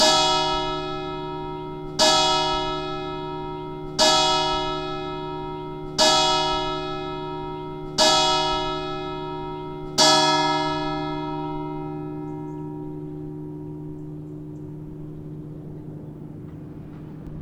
Catégorie l’alarme/reveil